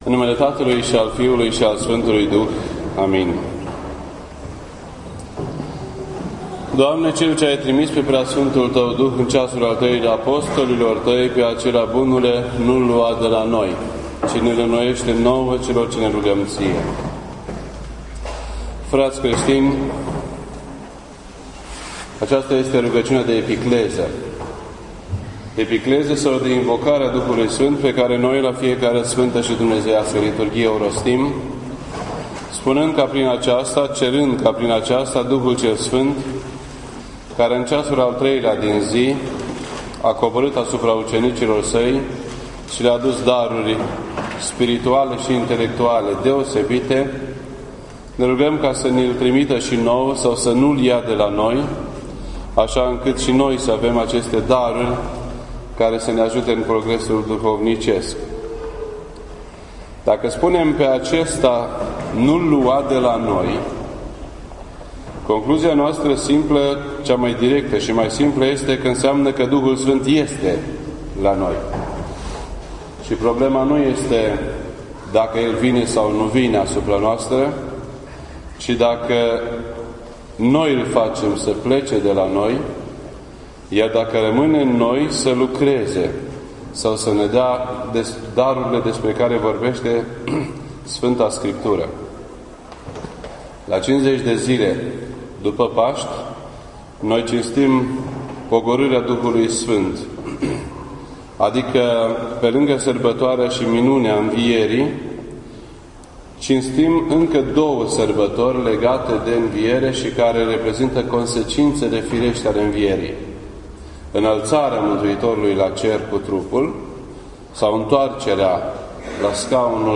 This entry was posted on Sunday, June 23rd, 2013 at 8:48 PM and is filed under Predici ortodoxe in format audio.